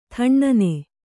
♪ thaṇṇane